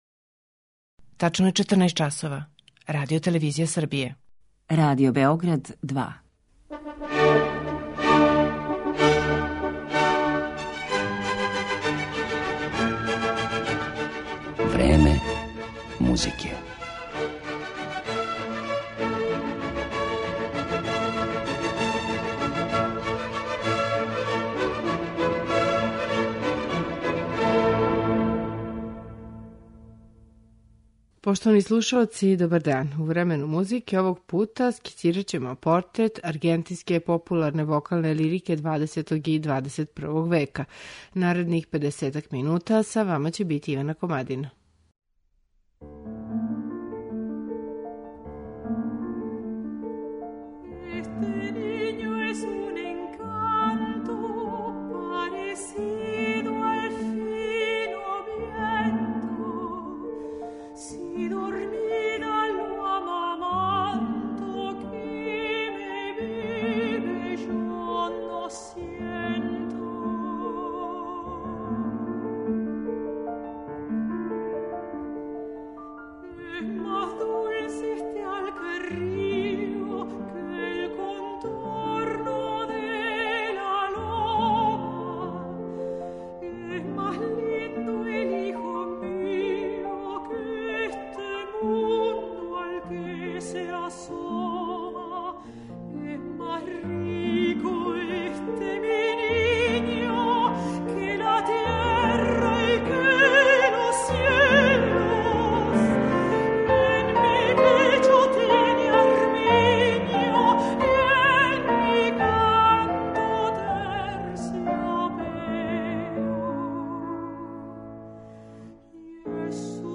Аргентинске уметничке песме